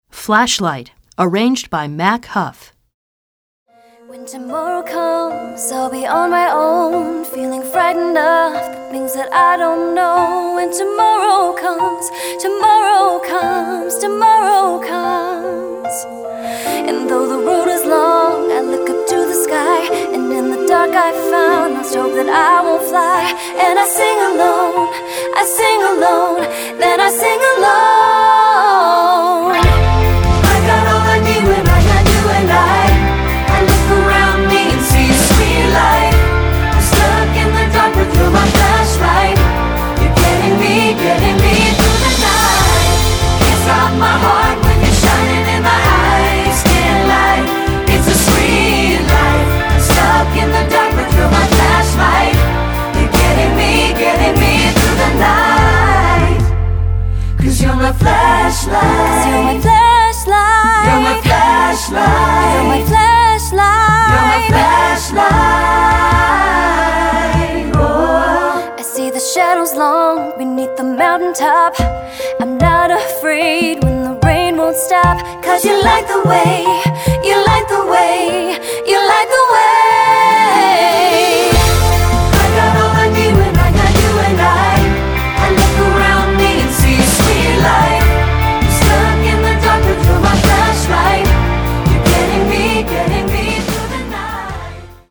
Choral Early 2000's Pop Movie/TV/Broadway
SATB